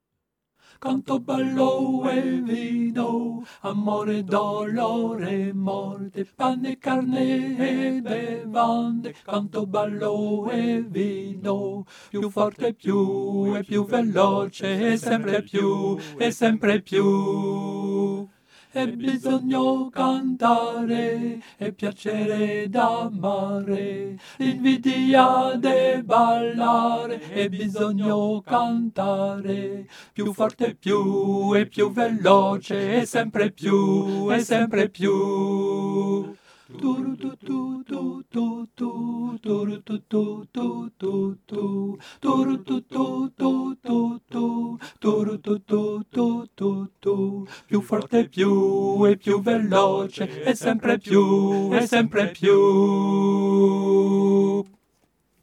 Ténor
Cette chanson doit rester légère,